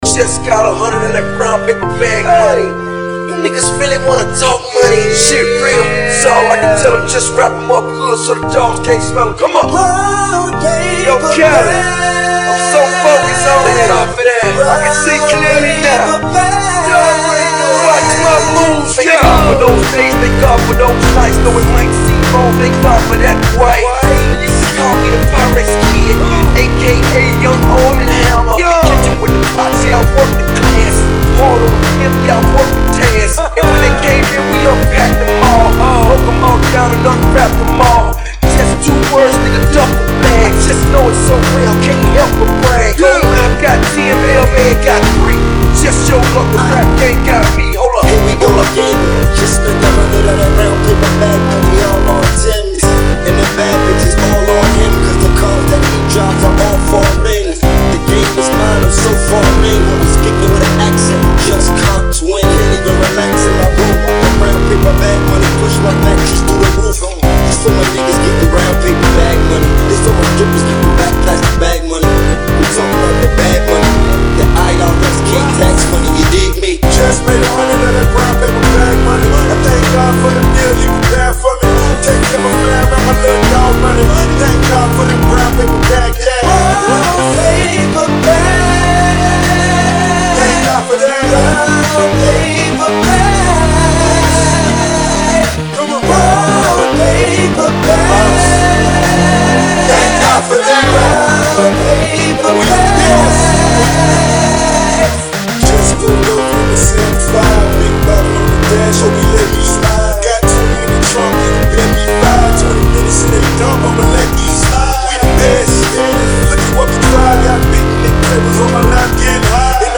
Filed under: Remix | Comments (1)